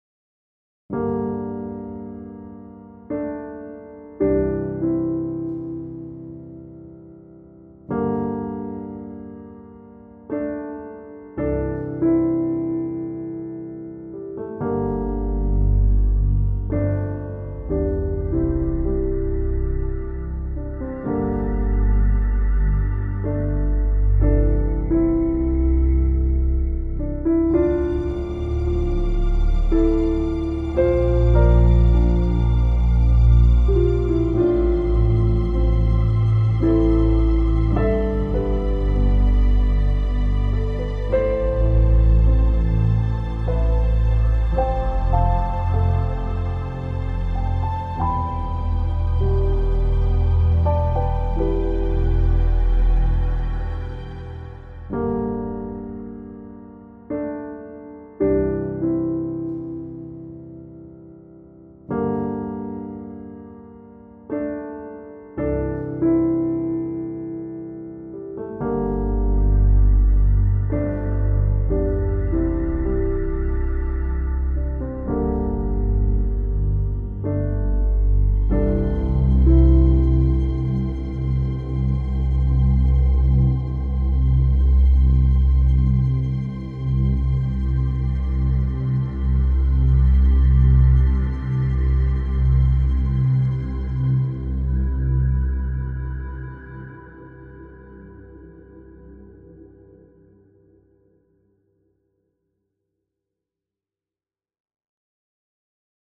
piano - calme - melodieux - melancolique - triste